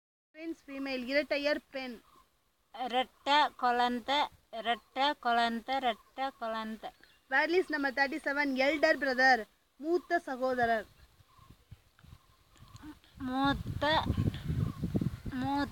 NotesThis is an elicitation of words for kinship terms, using the SPPEL Language Documentation Handbook.